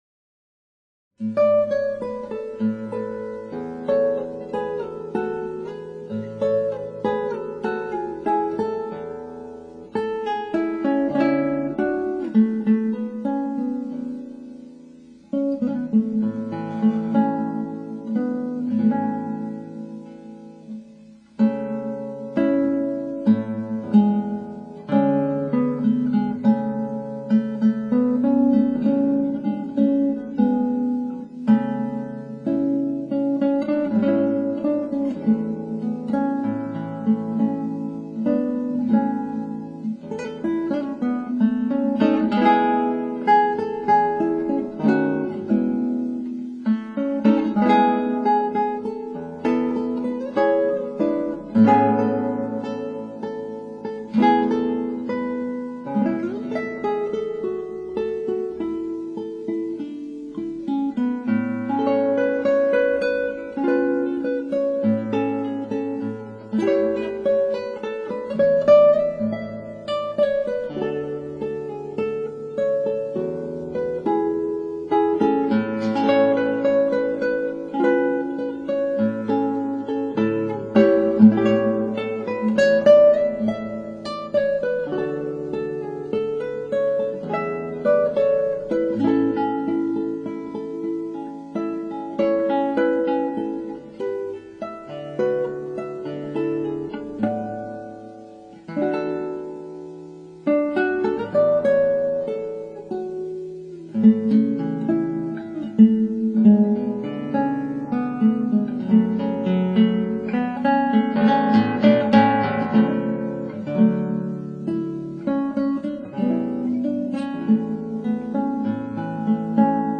クラシックギター　ストリーミング　コンサートサイト
ちょっとキレが甘いかなぁ。